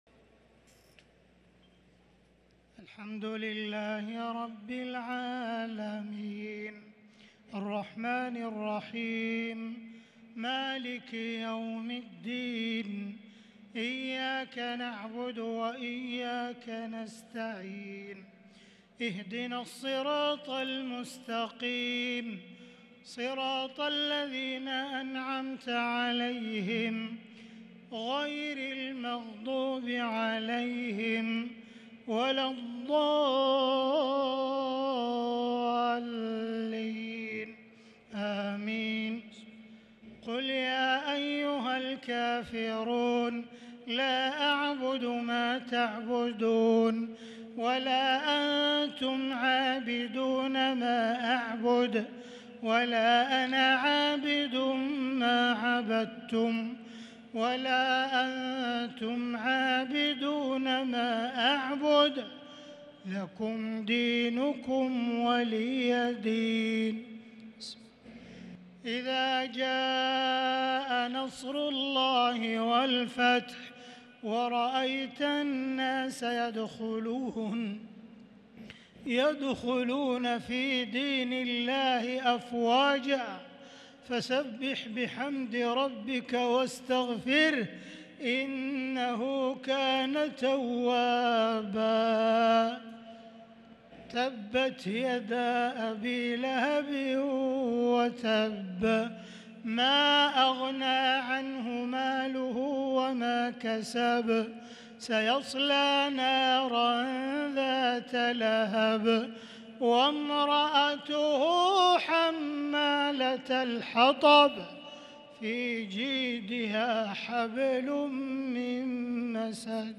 تراويح ليلة 29 رمضان 1443هـ من سورة الكافرون إلى سورة الناس | taraweeh 29 st niqht Ramadan 1443H from Surah Al-Kaafiroon to An-Naas > تراويح الحرم المكي عام 1443 🕋 > التراويح - تلاوات الحرمين